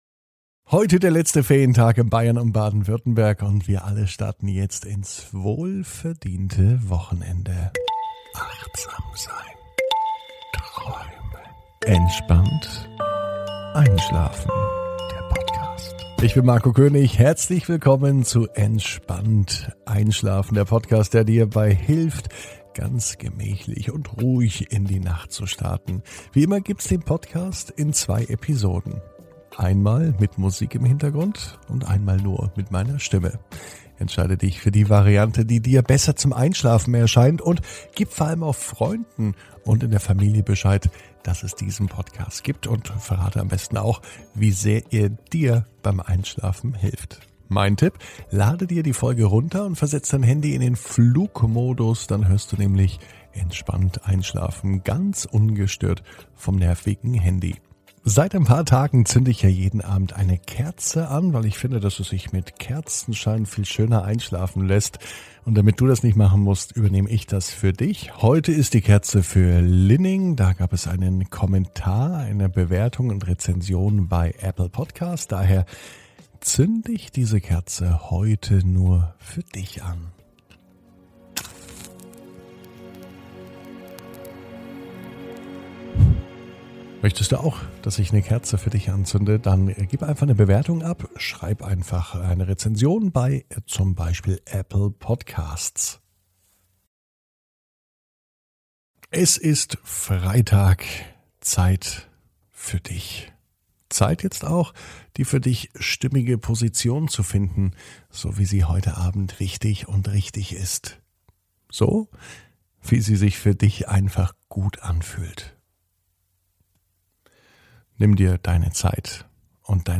(ohne Musik) Entspannt einschlafen am Freitag, 04.06.21 ~ Entspannt einschlafen - Meditation & Achtsamkeit für die Nacht Podcast